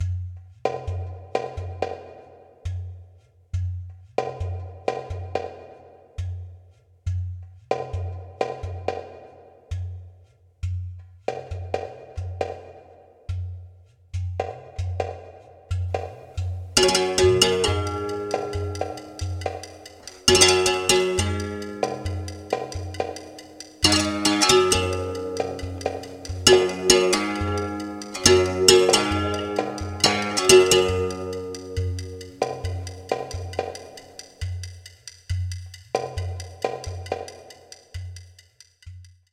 Mikrofonierung für afrikanisch/brasilianische Basstrommeln
Ich nehme schon seit einigen Jahren auf, gerade aber verstärkt tiefe Basstrommeln (wie Surdo oder Dundun) und bin dafür auf der Suche nach Tips und Hilfen.